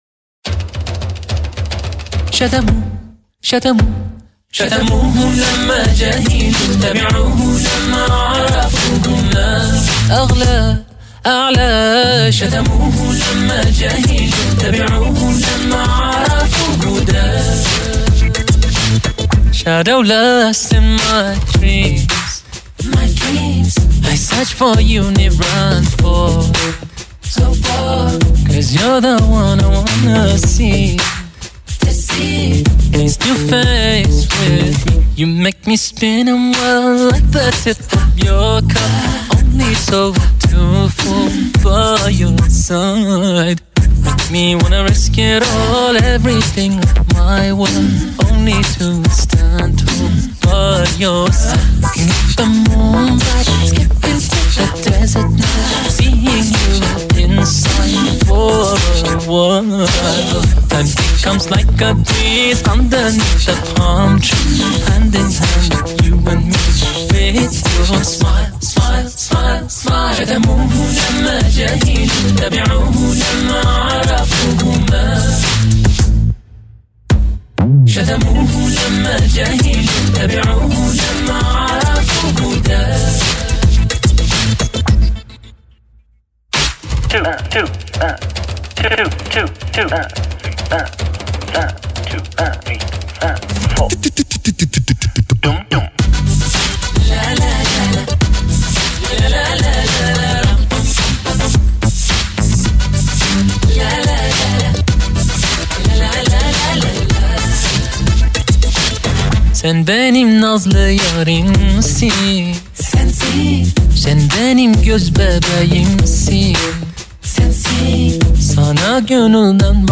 Hamd Naat & Nasheeds [Arabic & English]
Darood o Salam